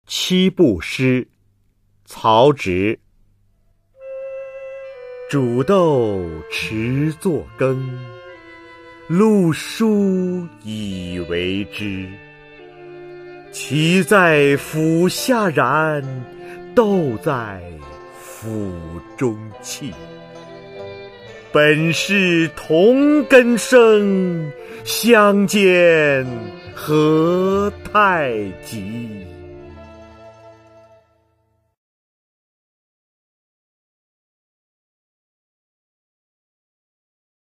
曹植-七步诗 朗诵
介绍： [两汉诗词诵读]曹植-七步诗 朗诵